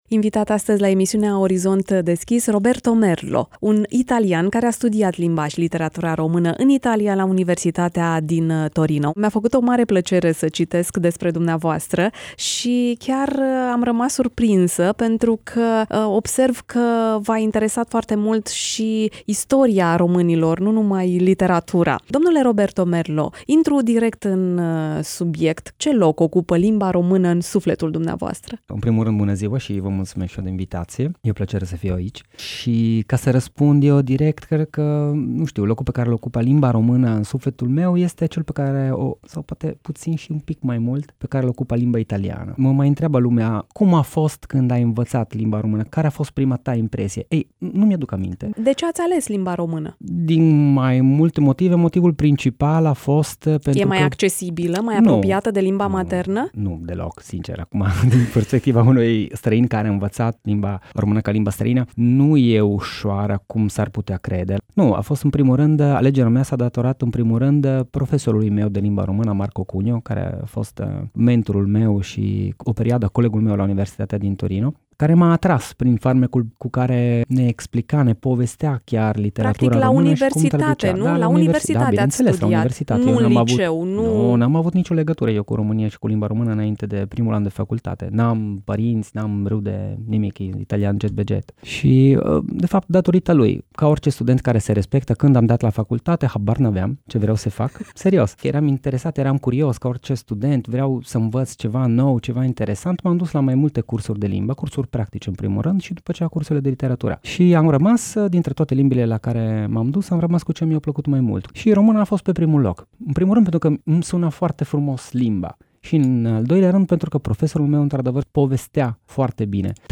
(INTERVIU)
Varianta audio a interviului: